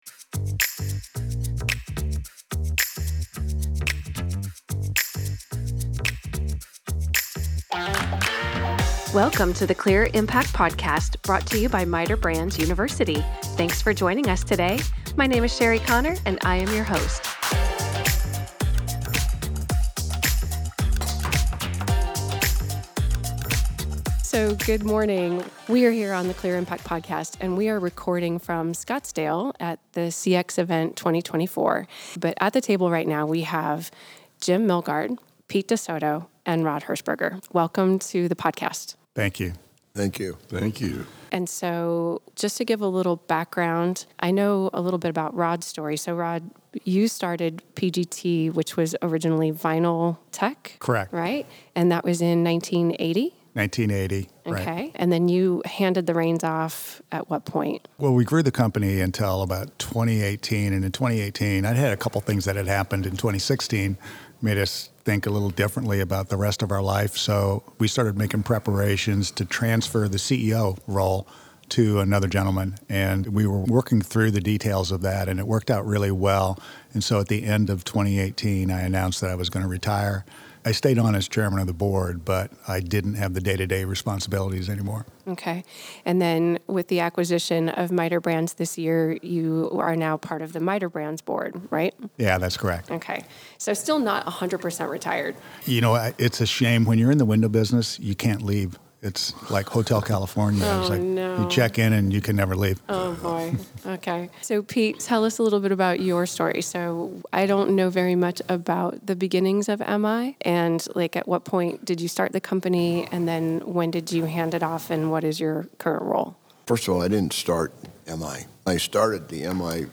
Grit, the theme of our CX event, is literally the foundation of MITER brands. And when legends gather, it's worth recording.